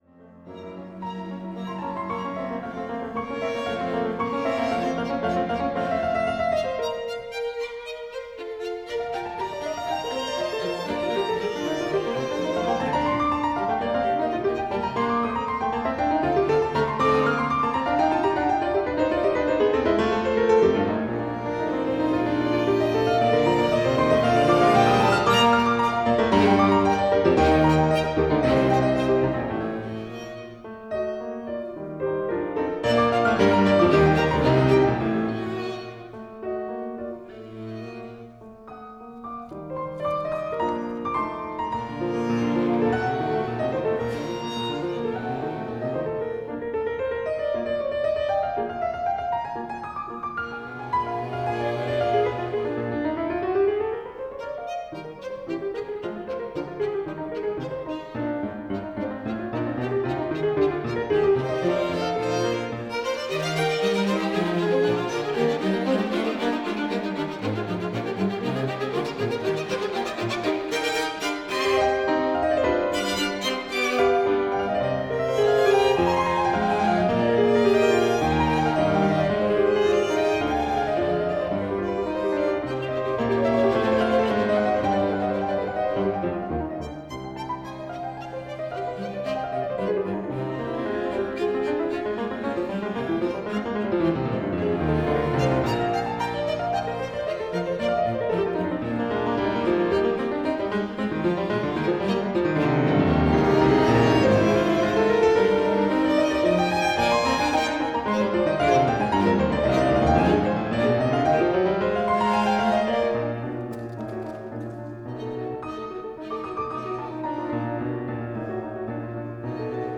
piano
String quartet
Recorded at the Small hall of the Moscow Conservatoire on March 13, 2007
1 микрофон - перед квартетом (SONY, стереомикрофон для минидиска. Немного шумит именно он. Для музыки мало подходит, но за неимением других микрофонов пришлось довольствоваться этим). Стереопара Октав МК-012 на рояль + YAMAHA MG8/2FX.